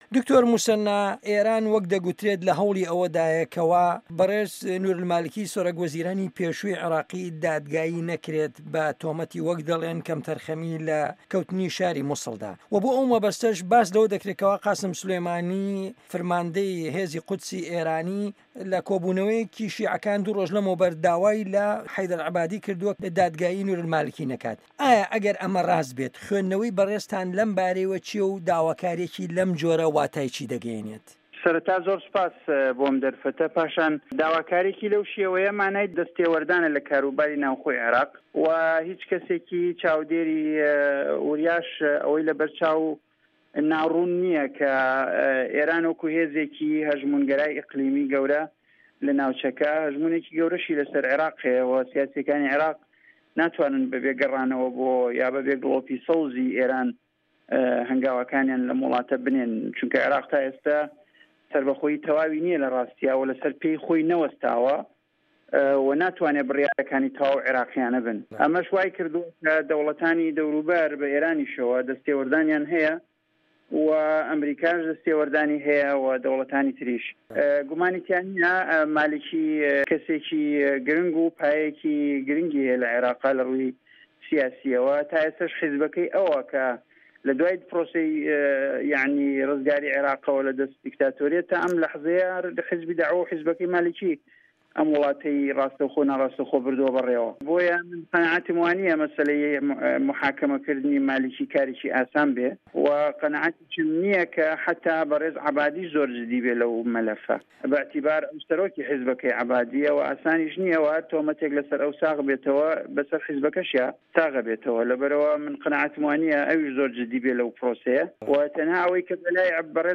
وتوێژ لەگەڵ دکتۆر موسەنا ئەمین